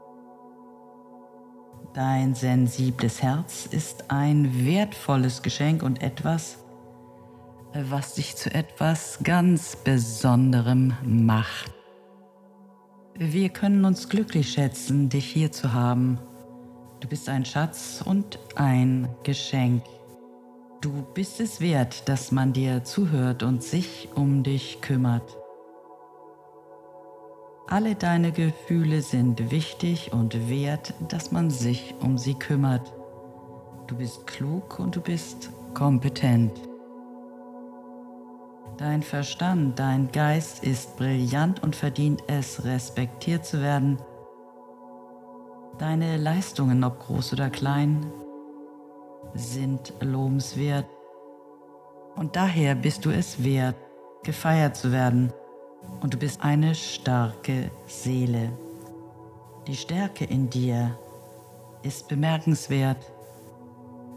Musik: Ja; Gesamtlänge: ca. 9 Minuten.